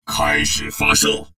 文件 文件历史 文件用途 全域文件用途 Enjo_skill_04.ogg （Ogg Vorbis声音文件，长度1.4秒，120 kbps，文件大小：20 KB） 源地址:地下城与勇士游戏语音 文件历史 点击某个日期/时间查看对应时刻的文件。